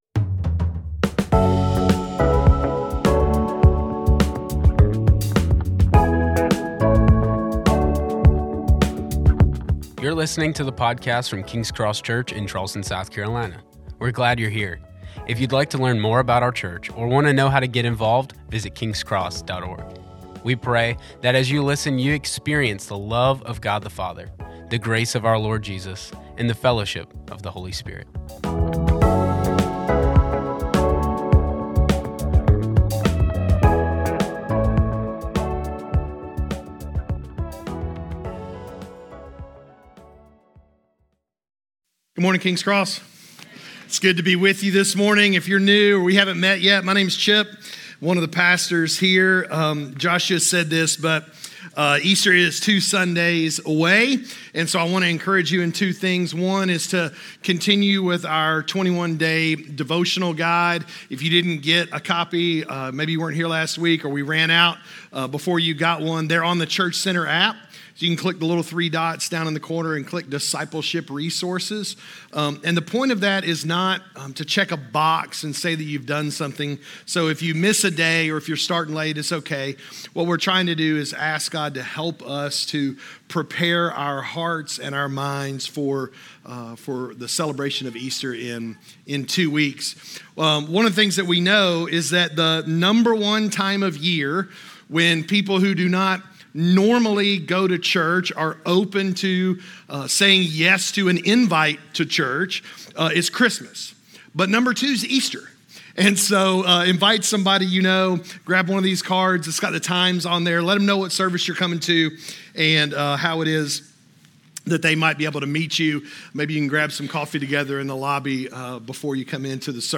Message
A message from the series "King Jesus."